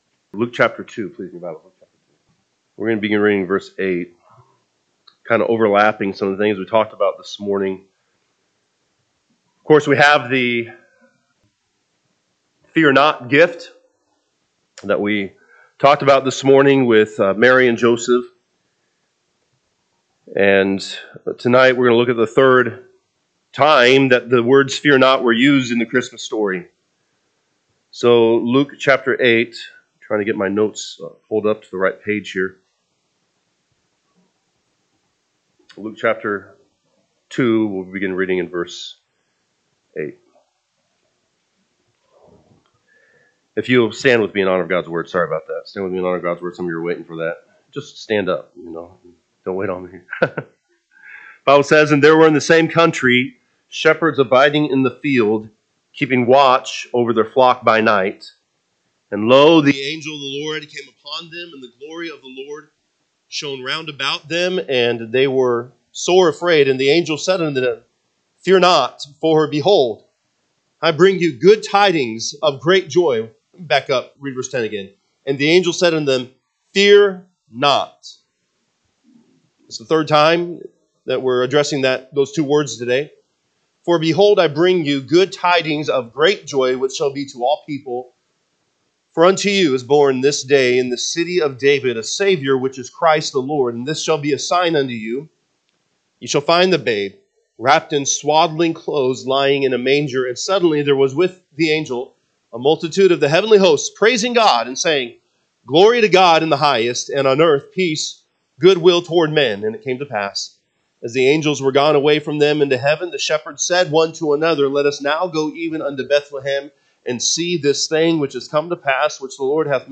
December 8, 2024 pm Service Luke 2:8-20 (KJB) 8 And there were in the same country shepherds abiding in the field, keeping watch over their flock by night. 9 And, lo, the angel of the Lor…